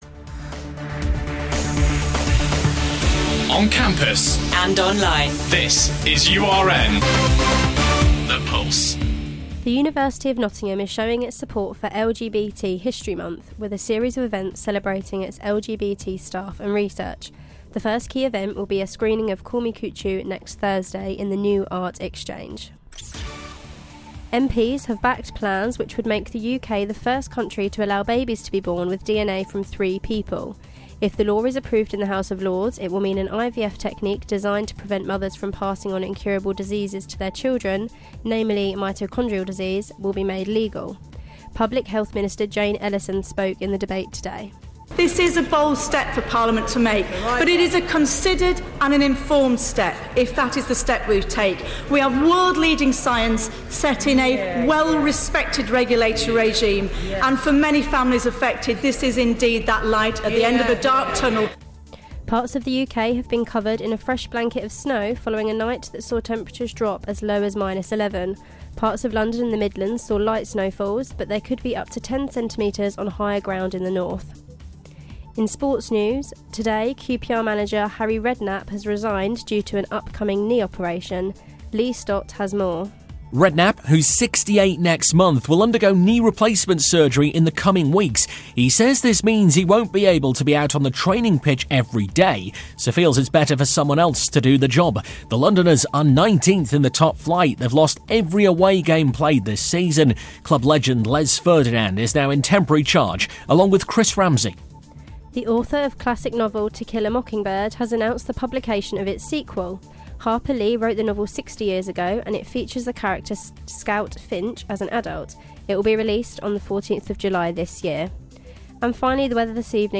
Your latest headlines - February 3rd